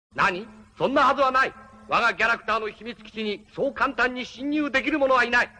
annoyed to have an intruder just when he's receiving a guest, converses with royalty in an unusually pleasant voice until he runs into the resentful heir to the kingdom, and his sneering side slowly gets the better of him.